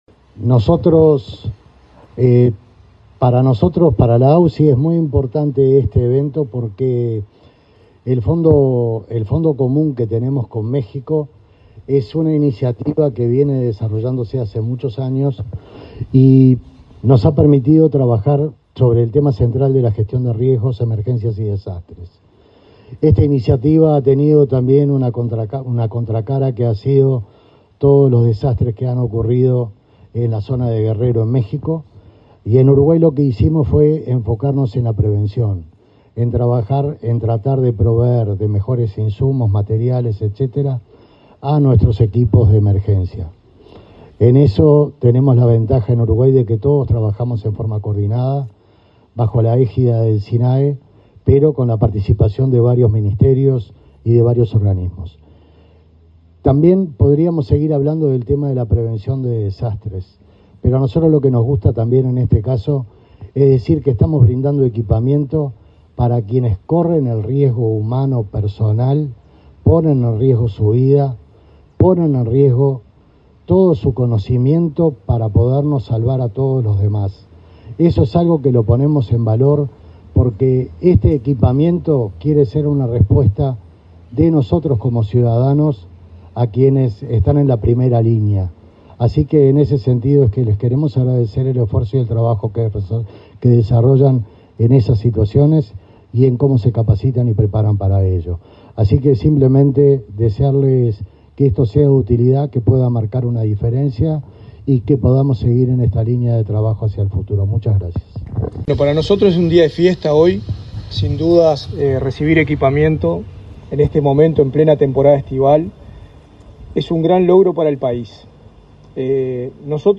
Palabras de autoridades en acto del Sinae
El director ejecutivo de la Agencia Uruguaya de Cooperación Internacional, Mariano Berro; el director nacional de Bomberos, Richard Barboza, y el titular del Sistema Nacional de Emergencias (Sinae), Santiago Caramés, participaron en el acto de entrega de una donación de la Embajada de México de insumos y equipamiento para la prevención y el combate de incendios forestales.